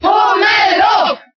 Category:Crowd cheers (SSBB) You cannot overwrite this file.
Jigglypuff_Cheer_German_SSBB.ogg.mp3